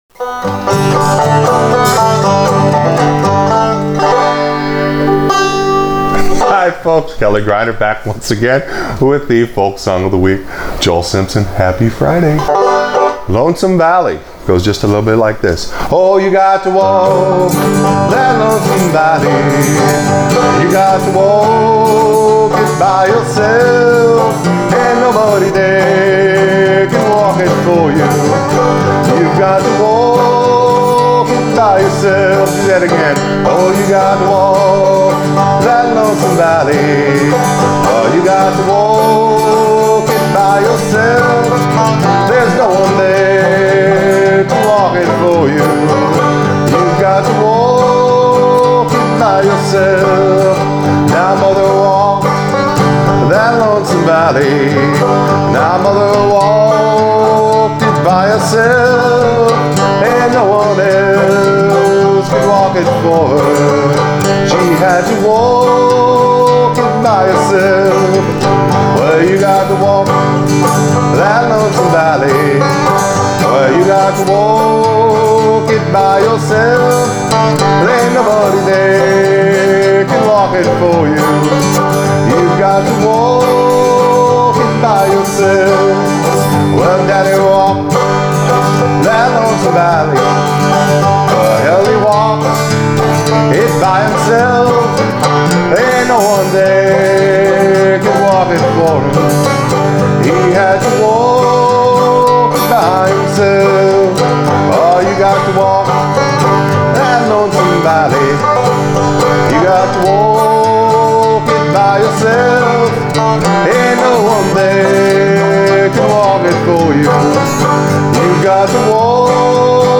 Folk Song Of The Week – Lonesome Valley Accompaniment for Frailing Banjo